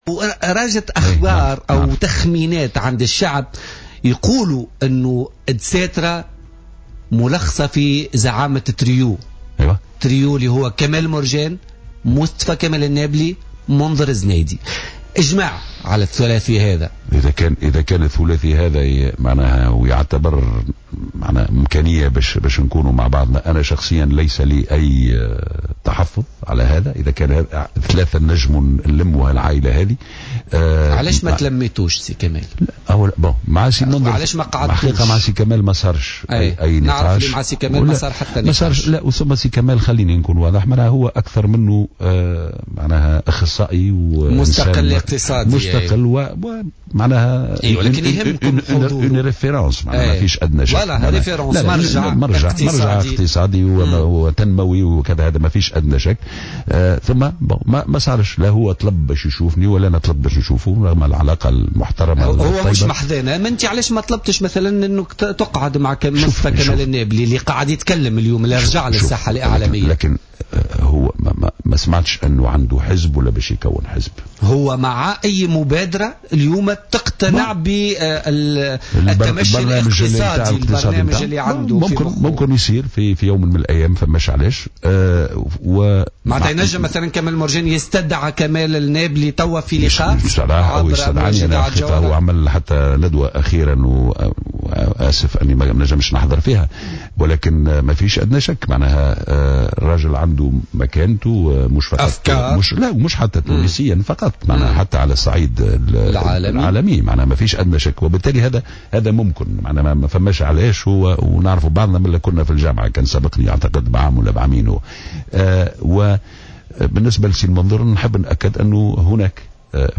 كشف رئيس حزب المبادرة، كمال مرجان، ضيف برنامج بوليتيكا اليوم الثلاثاء عن لقاءات جمعته مؤخرا بمنذر الزنايدي ومحادثات بين الطرفين حول إمكانية العمل المشترك.